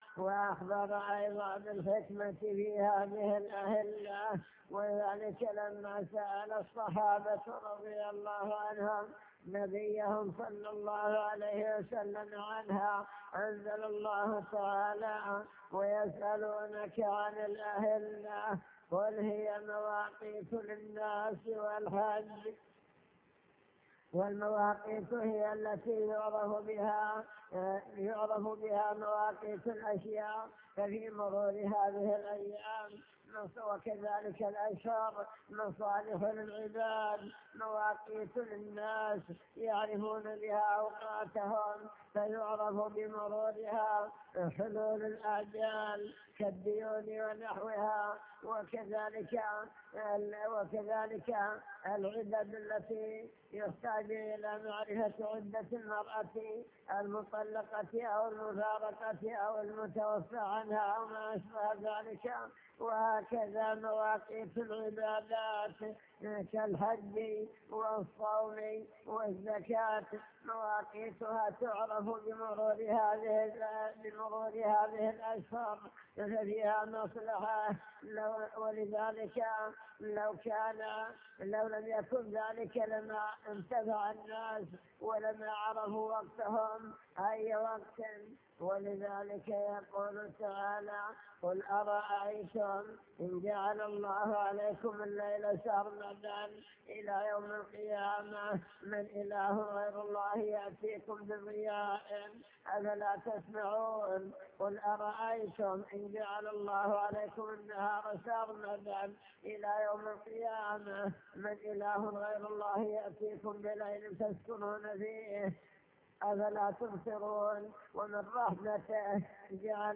المكتبة الصوتية  تسجيلات - محاضرات ودروس  محاضرة بعنوان المسلم بين عام مضى وعام حل